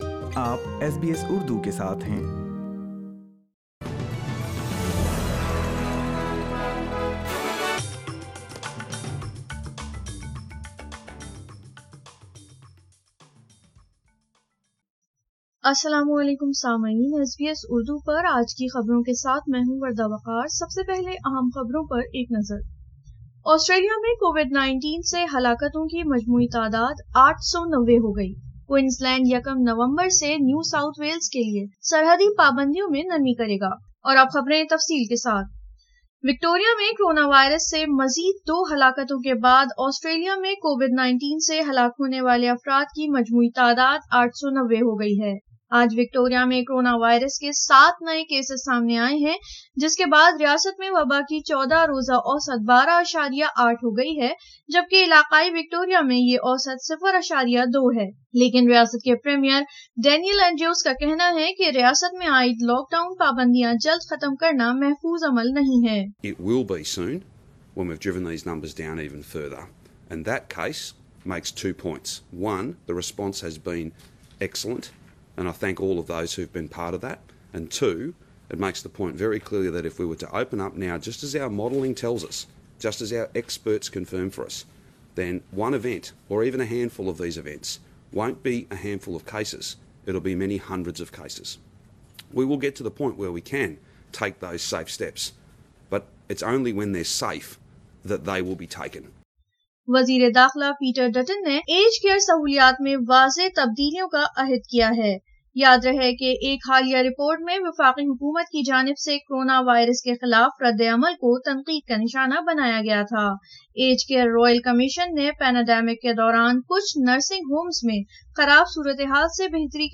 اردو خبریں 02 اکتوبر 2020